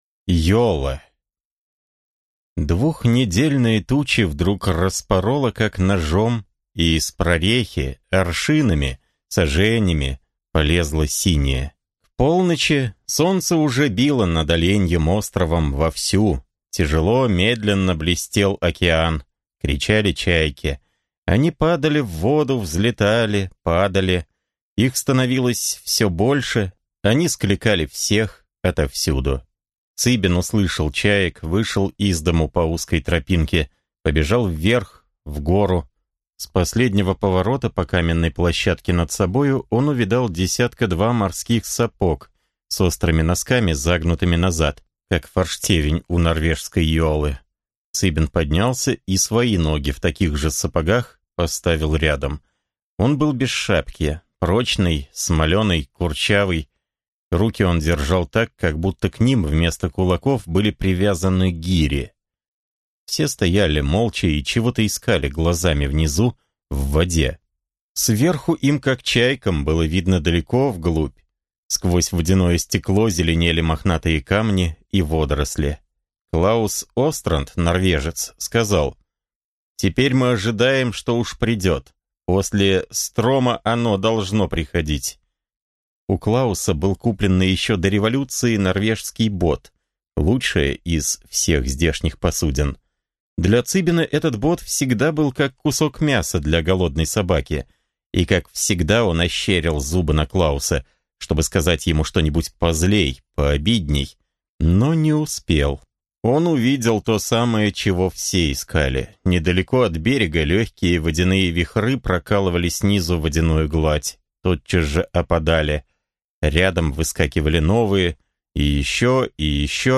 Аудиокнига Ёла. Африка. Мученица науки | Библиотека аудиокниг